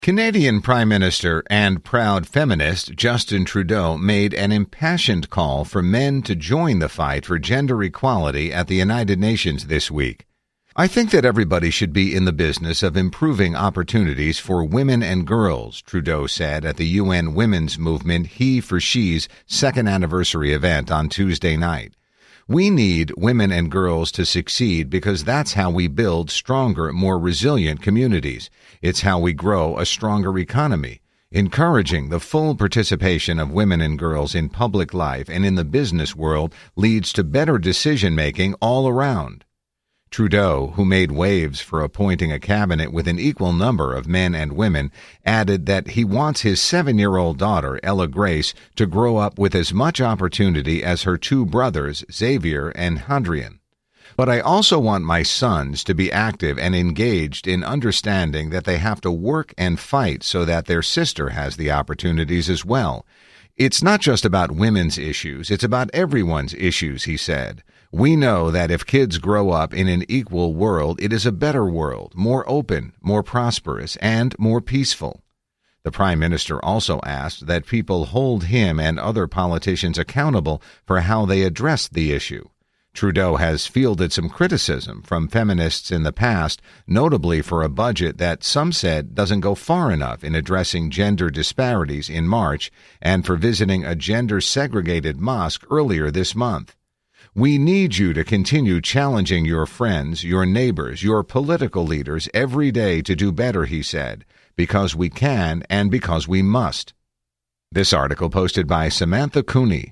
Canadian Prime Minister and proud feminist Justin Trudeau made an impassioned call for men to join the fight for gender equality at the United Nations this week.